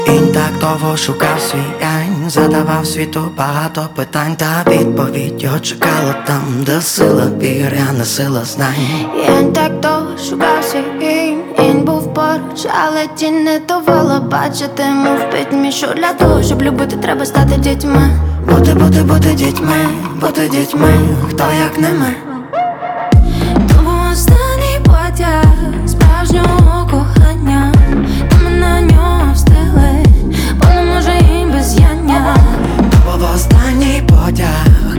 Жанр: Поп / Украинские
# Pop